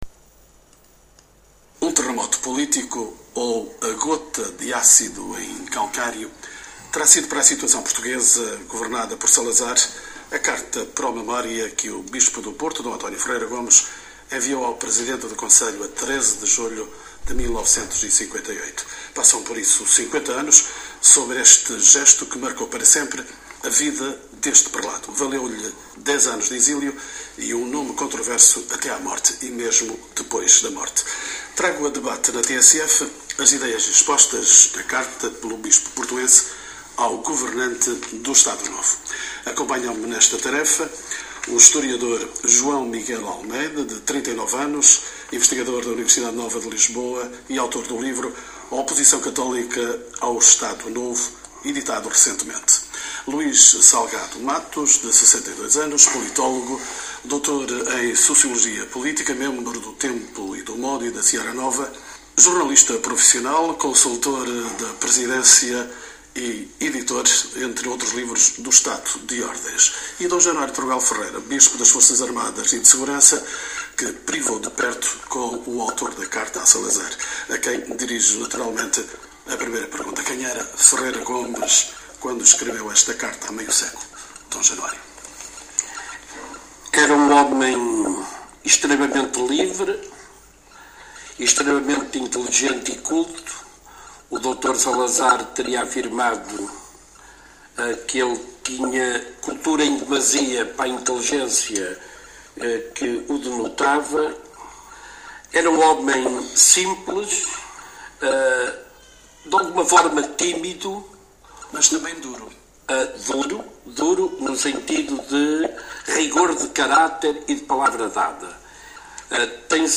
...........[Debate na TSF sobre o 50º aniversário da Carta a Salazar]